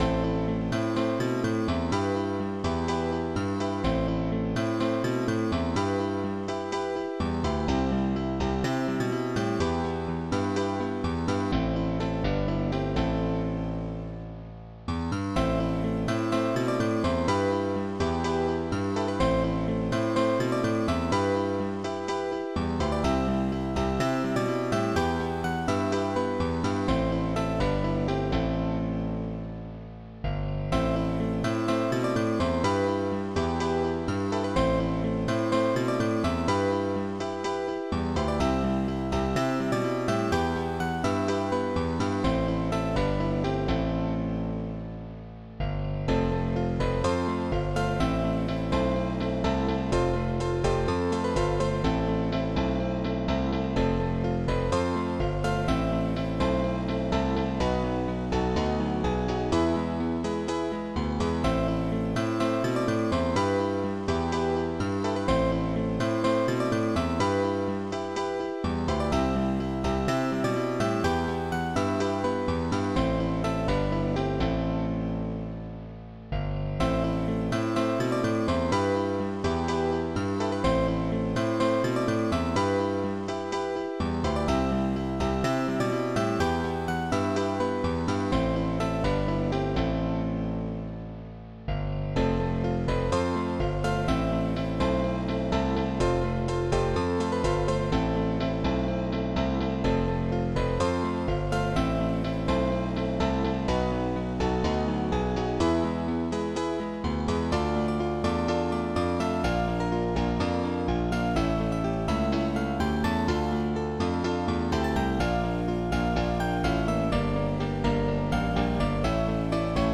OctaMED Module
Type MED/OctaMED (4ch)
ST-90:grandpiano1